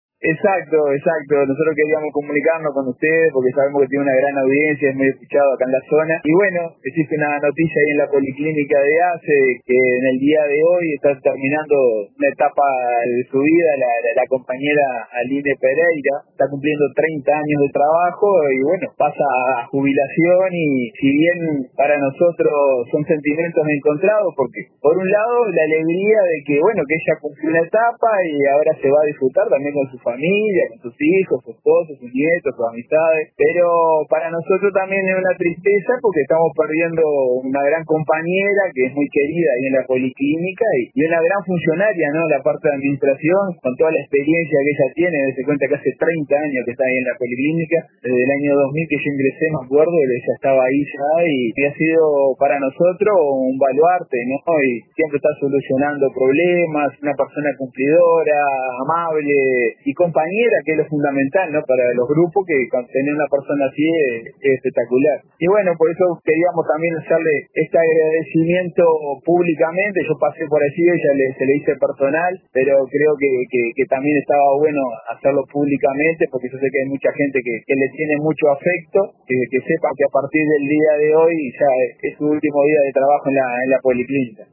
dedicó sentidas palabras a la funcionaria en una entrevista con el informativo central de RADIO RBC.